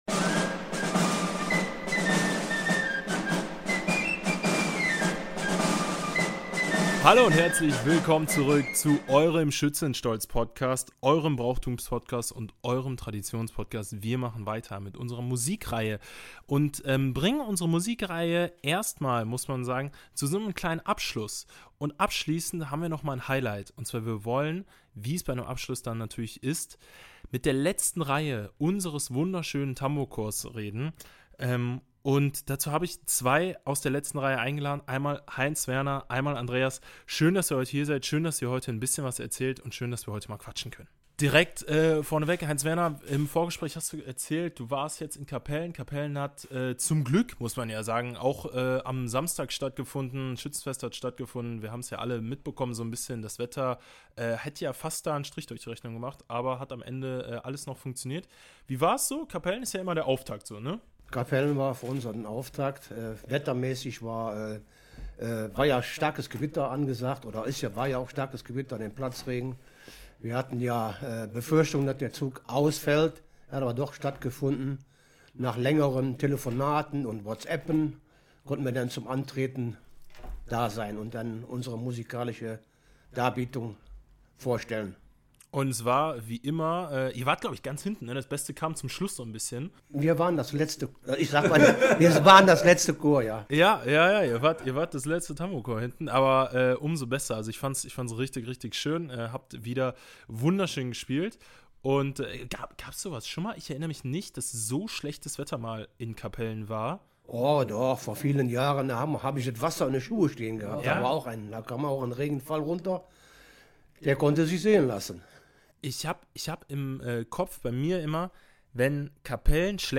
Über ein halbes Jahrhundert im Dienst der Marschmusik: In dieser Episode von Schützenstolz sprechen zwei Urgesteine des Tambourcorps Wevelinghoven über ihr Leben zwischen Proben, Festumzügen und Kameradschaft.